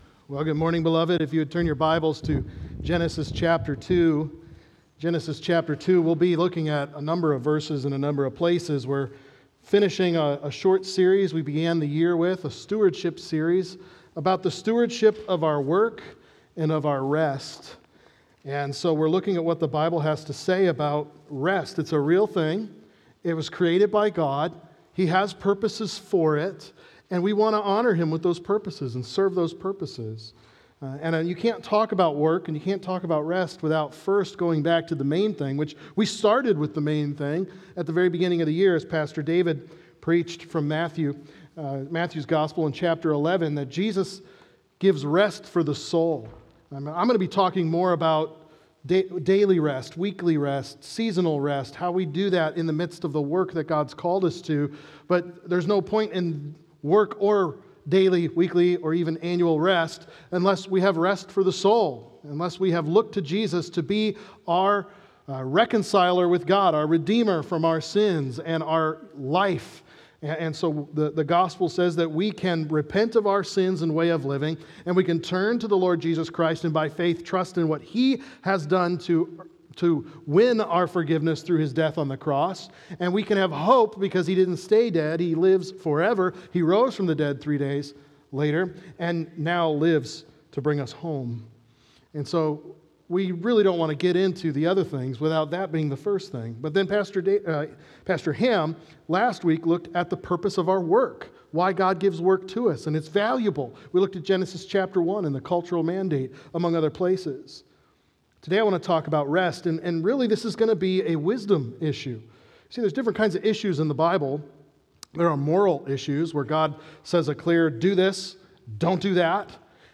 Learn To Rest | Baptist Church in Jamestown, Ohio, dedicated to a spirit of unity, prayer, and spiritual growth